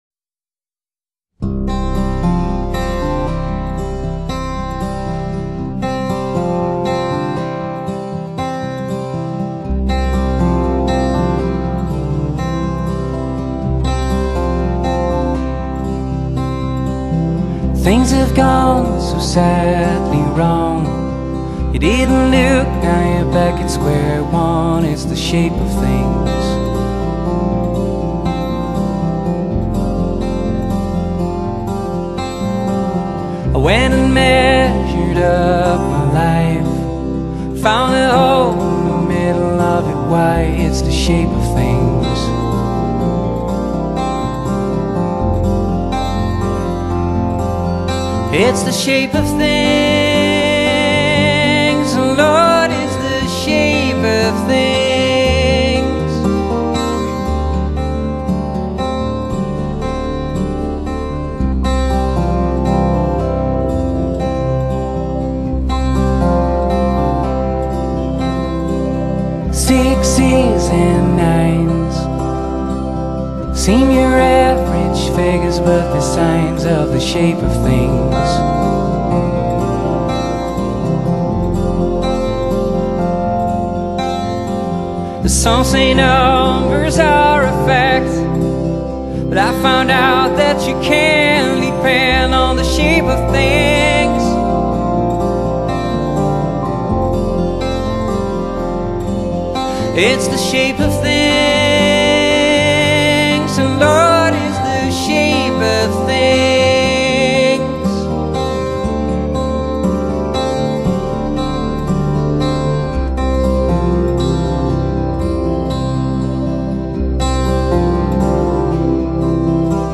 比珍珠還炫目的弦樂聲配合晶瑩剔透的人聲組合